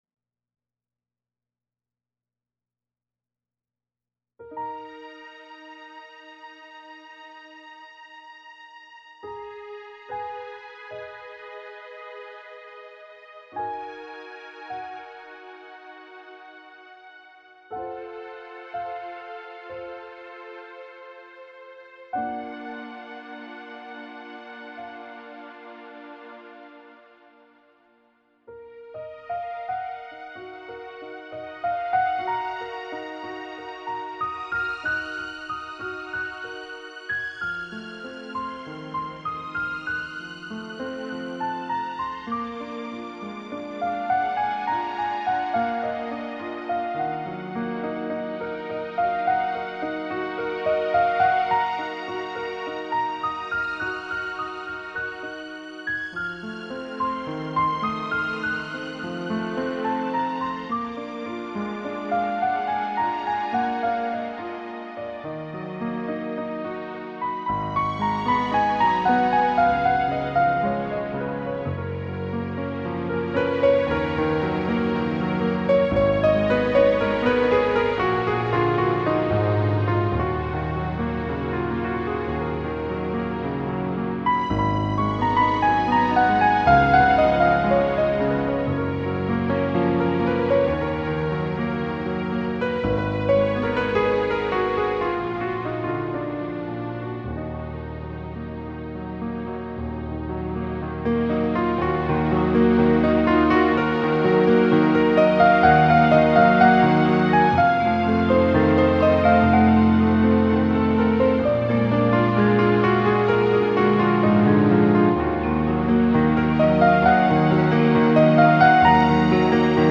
让我们随着指尖流泻的爱情旋律陶醉吧！
献给乐圣贝多芬的钢琴曲